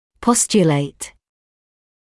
[‘pɔstjəleɪt][‘постйэлэйт]постулировать; принимать без доказательств